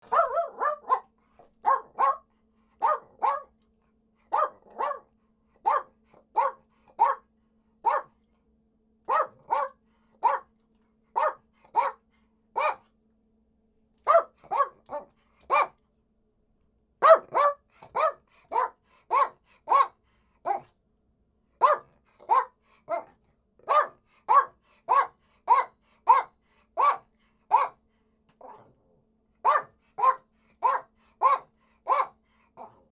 dog-barking Soundboard: Play Instant Sound Effect Button
Dog Barking Sound